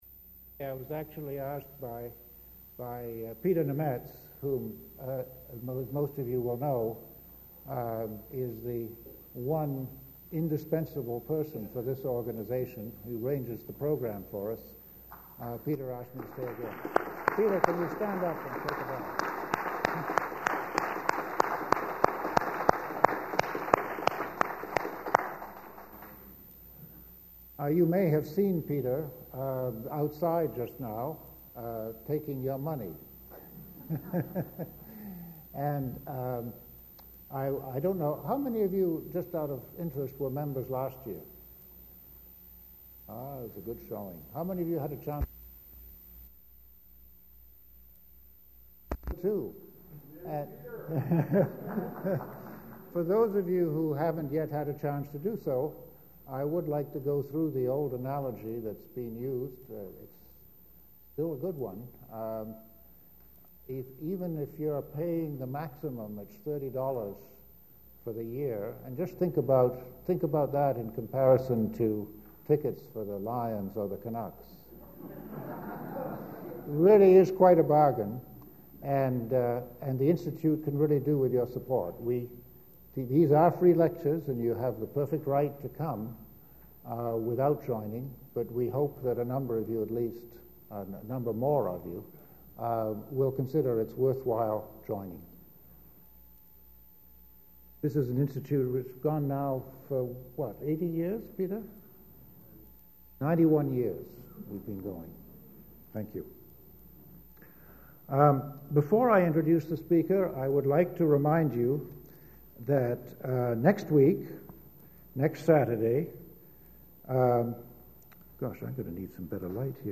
Vancouver Institute lecture